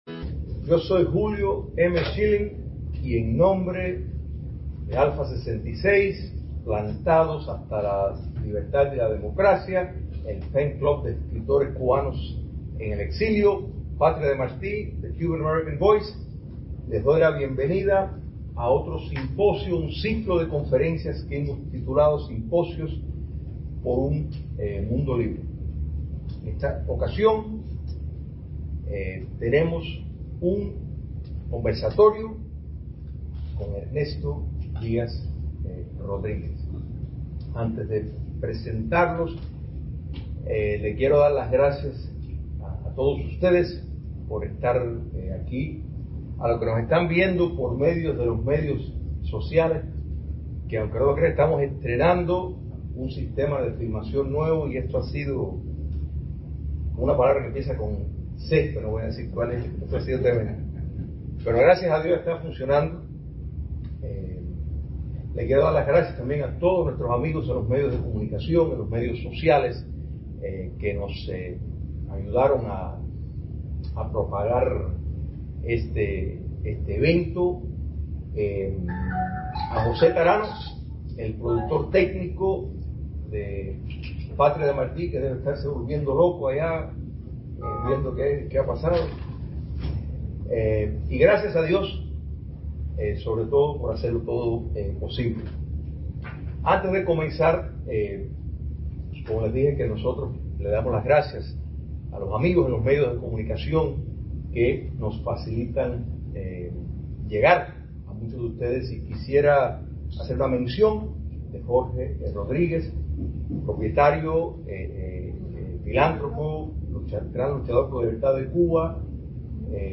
Simposio